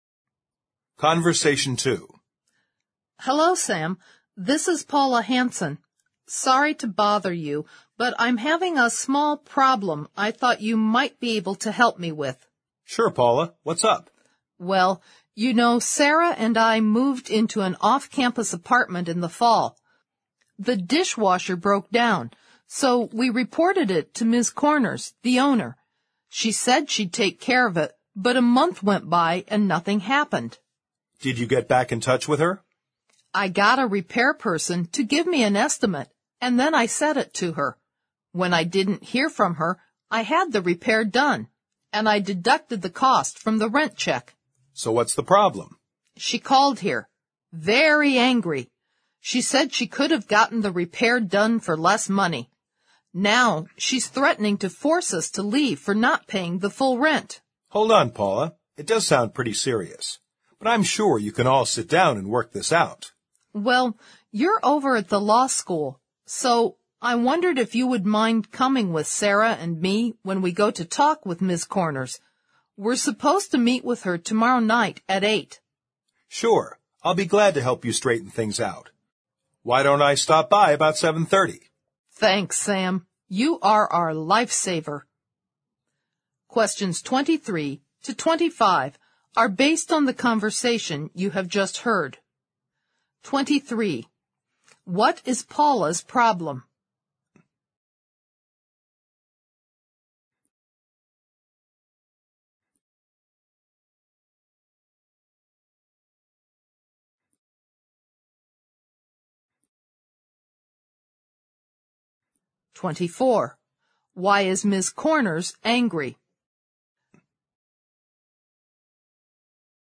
Conversation Two